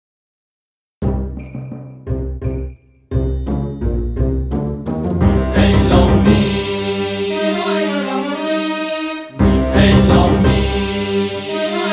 سرودهای روز معلم
بی‌کلام